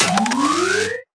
Media:RA_Jessie_Evo.wav UI音效 RA 在角色详情页面点击初级、经典和高手形态选项卡触发的音效